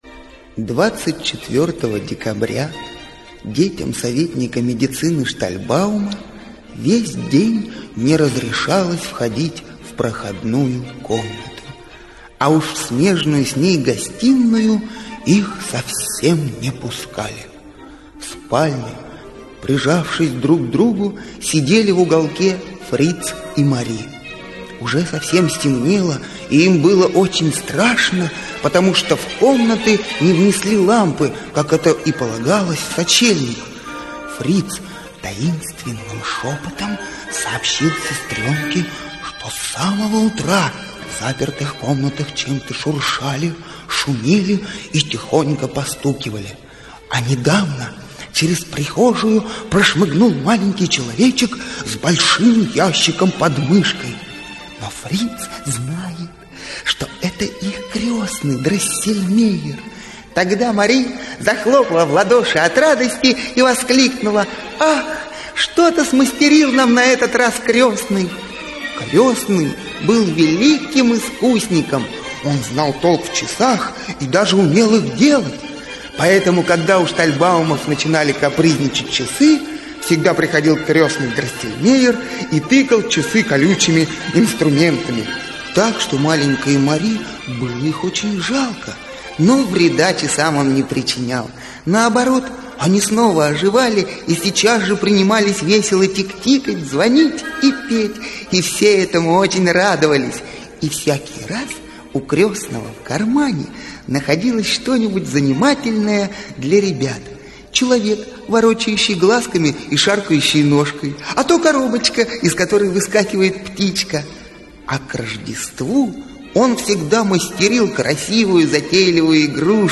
Аудиокнига Щелкунчик | Библиотека аудиокниг